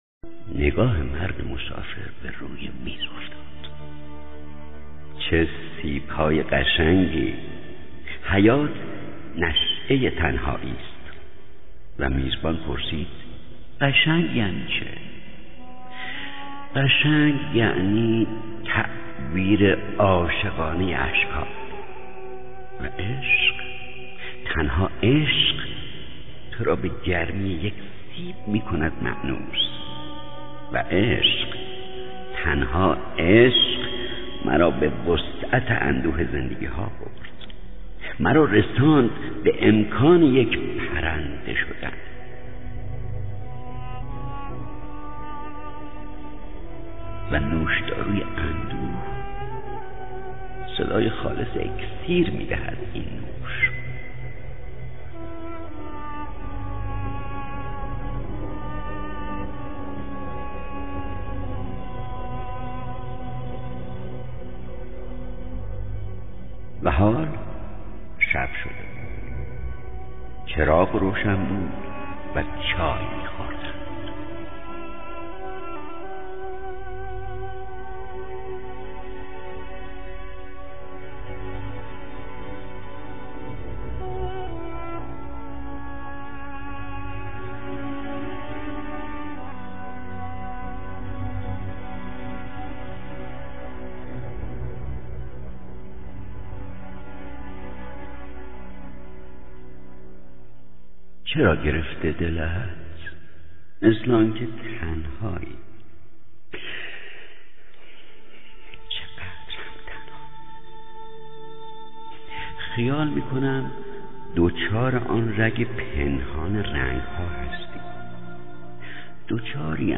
سهراب (با صدای خسرو شکیبایی)